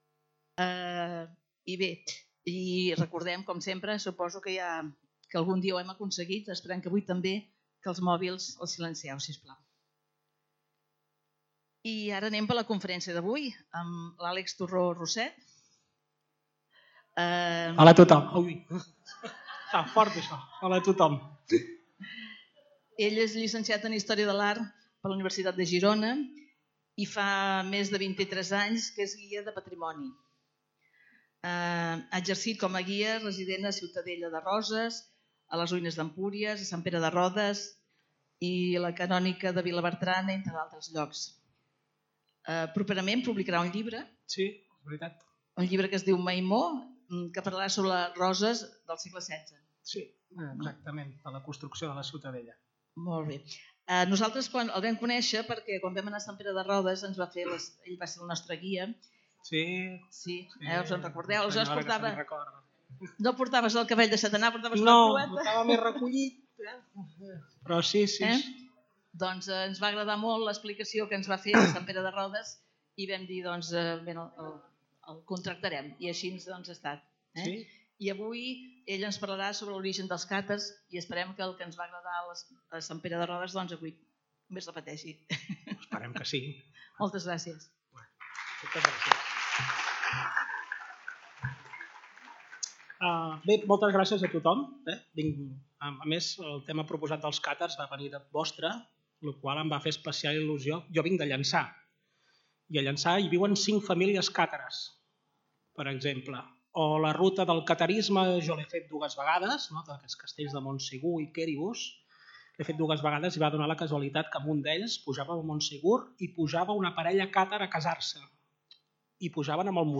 Lloc: Centre Cultural Calisay
Categoria: Conferències